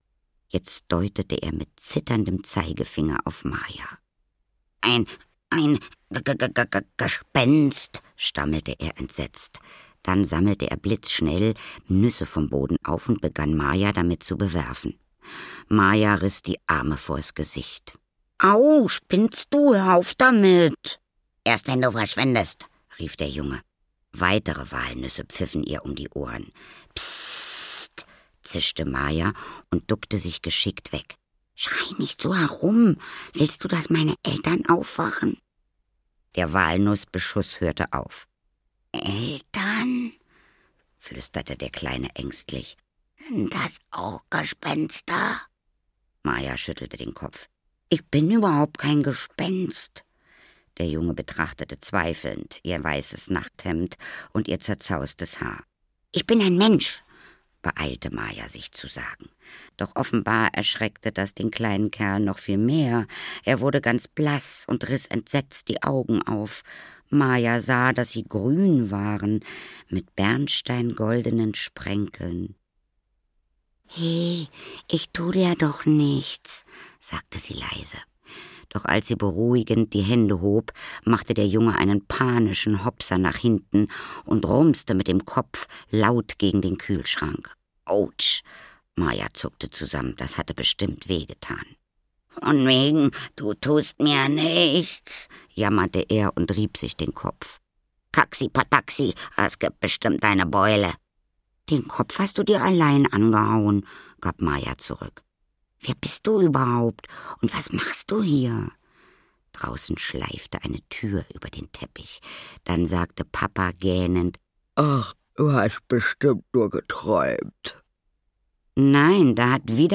Ein Baum für Tomti - Nina Blazon - Hörbuch